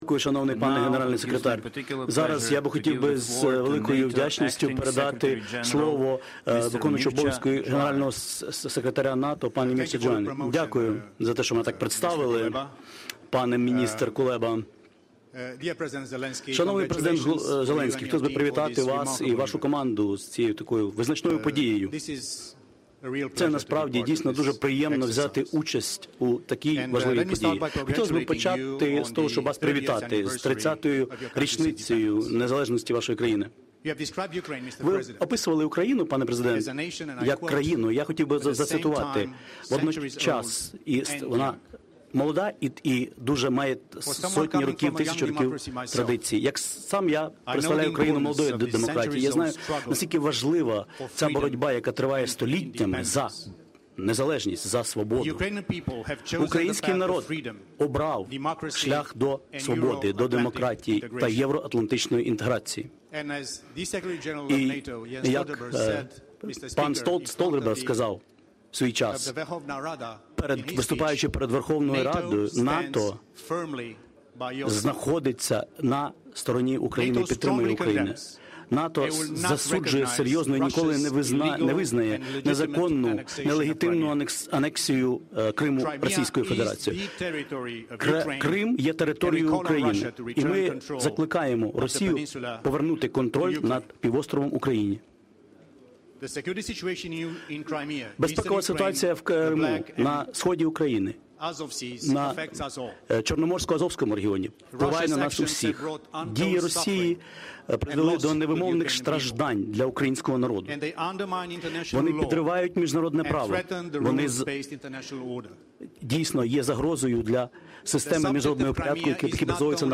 Remarks
by NATO Deputy Secretary General Mircea Geoană at the Crimea Platform Inaugural Summit